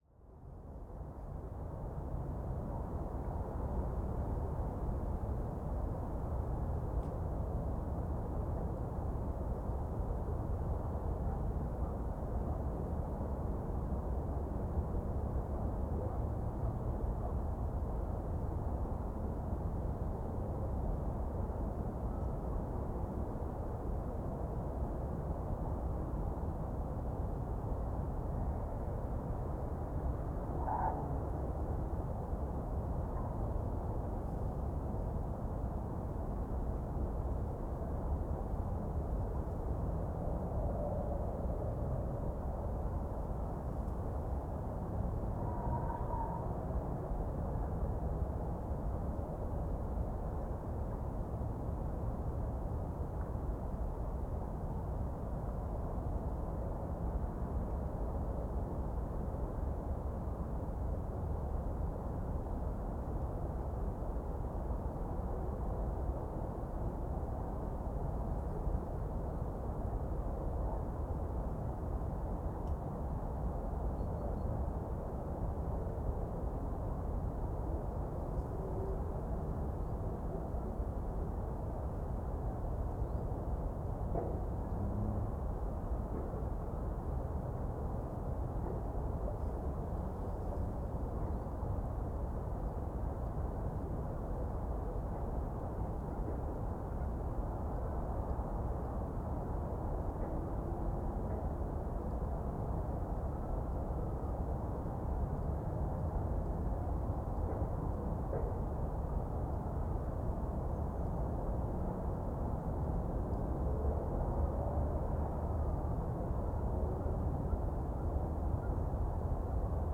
AMBUrbn_Distant City - Ambience - DC04, Far Distance, City Rumble, Traffic, Distant Siren, Autumn_WWA_DCMP_KM184s-ORTF.ogg